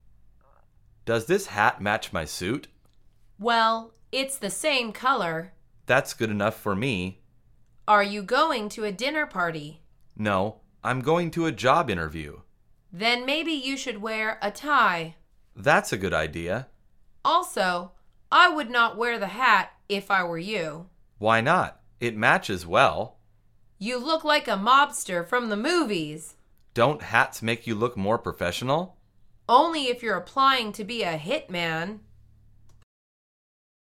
مجموعه مکالمات ساده و آسان انگلیسی – درس شماره دهم از فصل خرید: کلاه